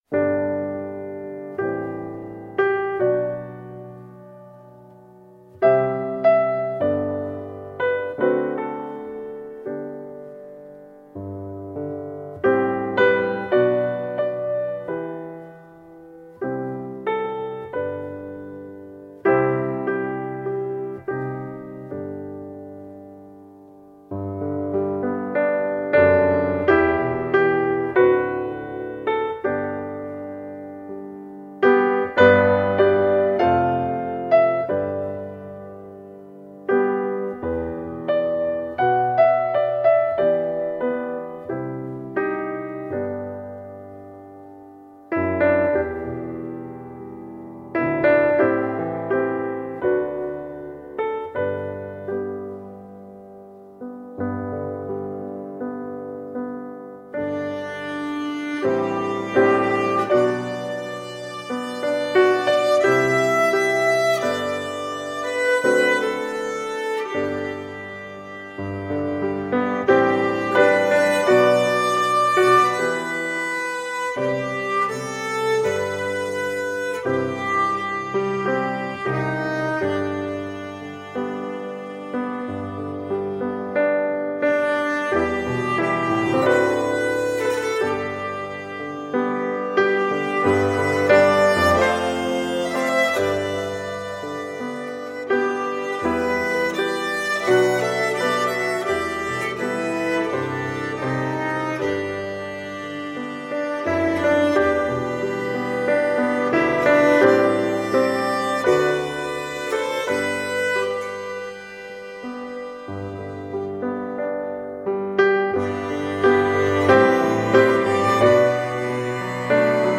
New age meets indian cello.
Tagged as: World, Folk, World Influenced